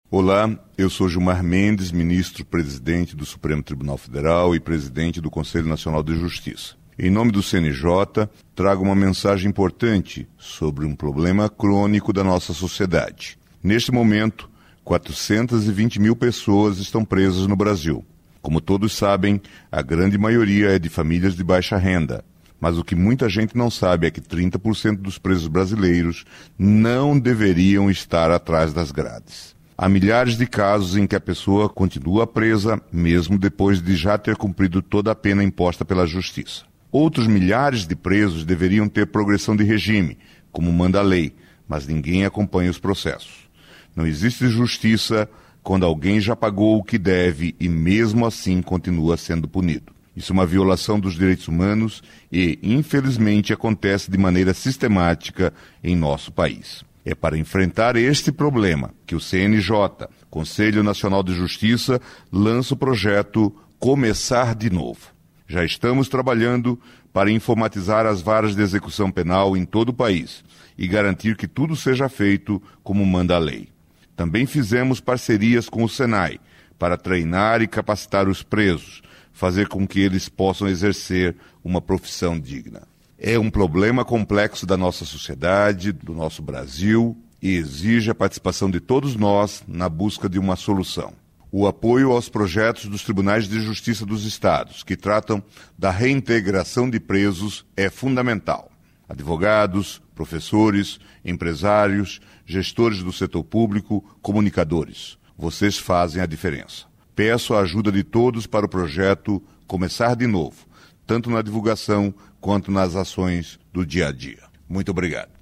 O apelo do ministro foi gravado em mensagem que será encaminhada à Associação Brasileira de Emissoras de Rádio e TV (Abert) parceira do programa, para veiculação em todas as 2,6 mil emissoras de rádio associadas  à instituição em todo o país.